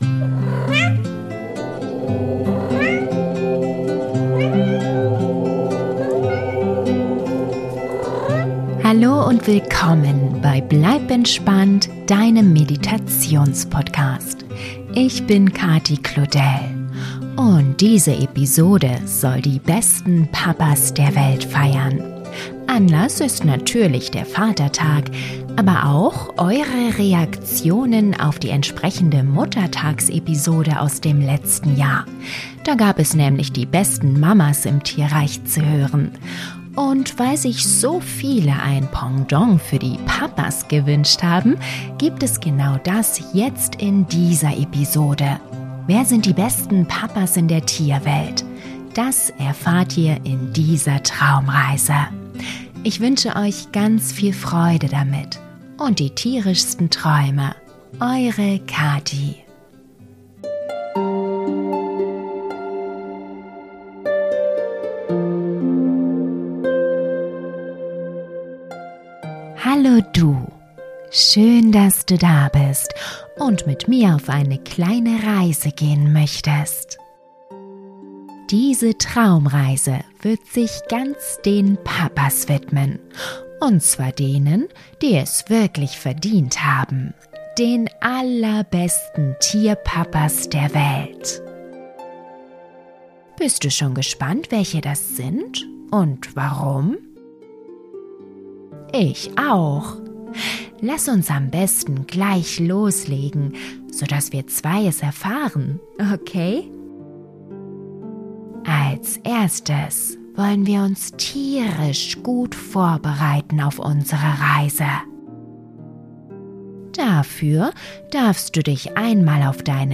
Traumreise für Kinder - Die besten Papas der Welt - Vatertag Geschichte ~ Bleib entspannt!